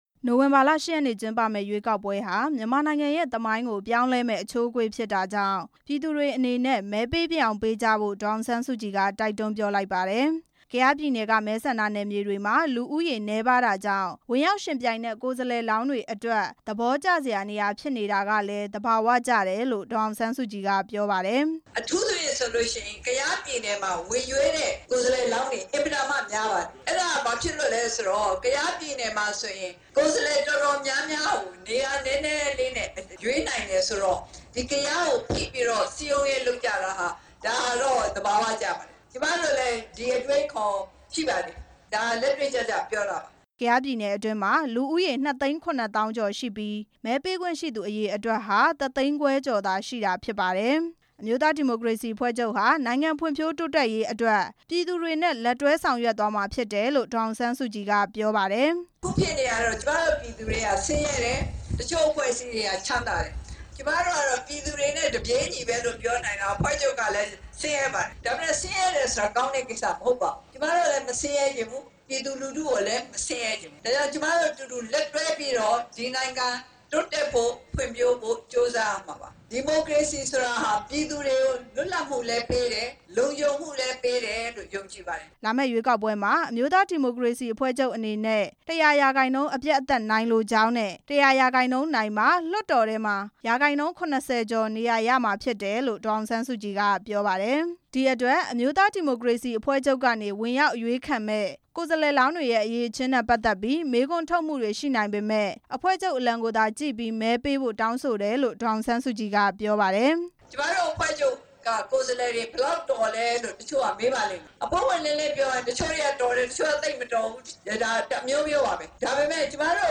ဒေါ်အောင်ဆန်းစုကြည်ရဲ့ ပြောကြားချက်များ
ကယားပြည်နယ် ဒီမောဆိုမြို့နယ် ကက်သလစ် ဘုန်းတော်ကြီးကျောင်းဝင်းအတွင်းမှာကျင်းပတဲ့ ရွေးကောက်ပွဲ ဆိုင်ရာ အသိပညာပေးဟောပြောပွဲမှာ ဒေါ်အောင်ဆန်းစုကြည်က ဒေသခံလူထုအနေနဲ့ မဲပေးကြဖို့ တိုက်တွန်းပြောဆိုခဲ့တာဖြစ်ပါတယ်။